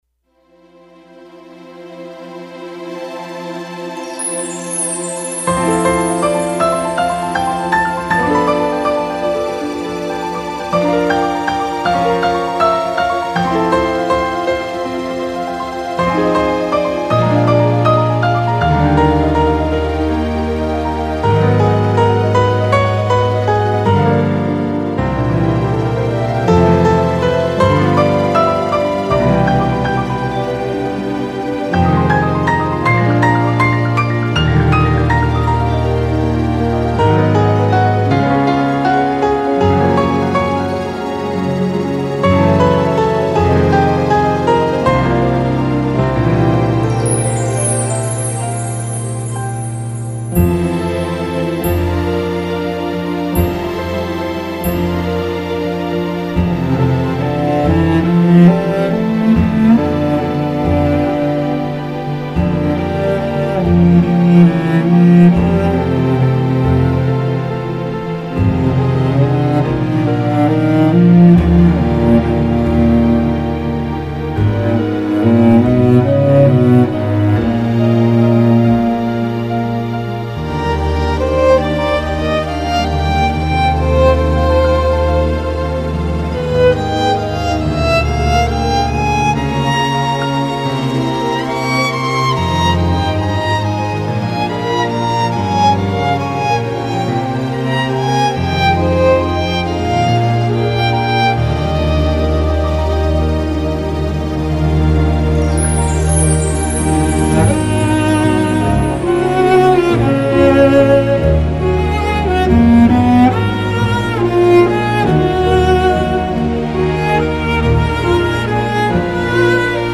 浪漫新世纪音乐